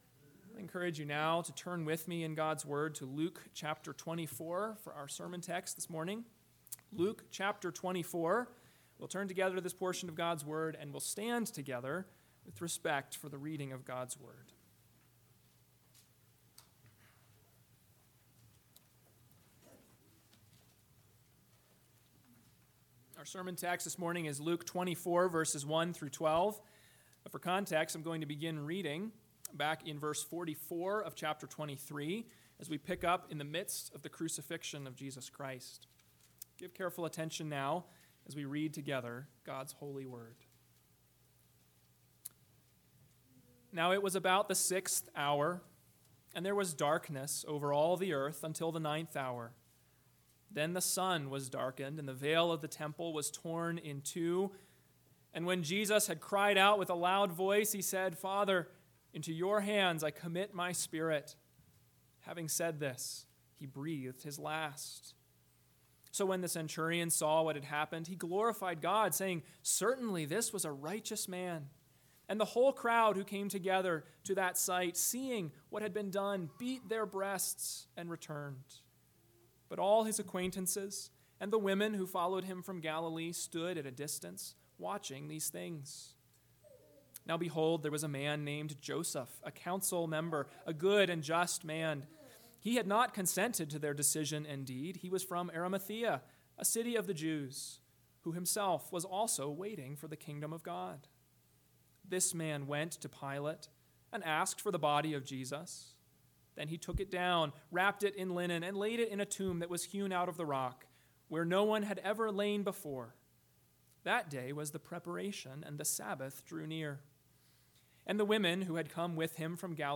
Easter Sunday
AM Sermon